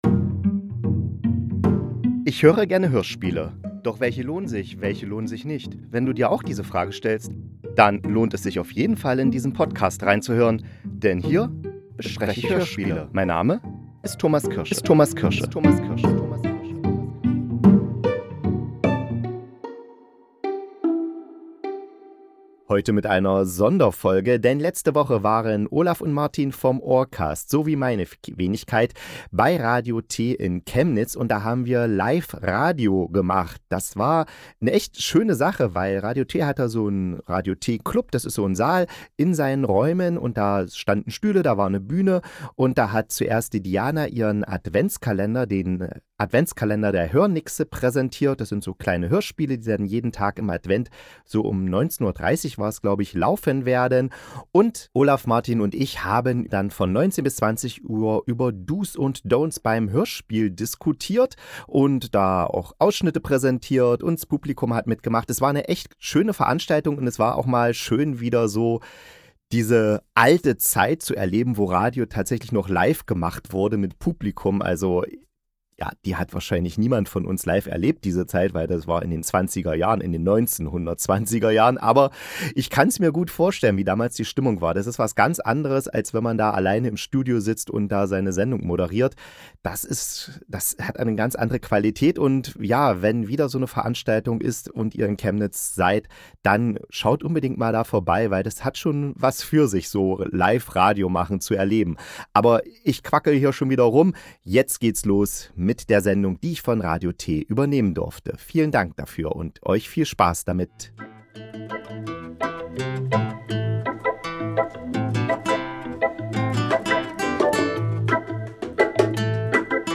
Live-Veranstaltung: Do’s und Dont’s im Hörspiel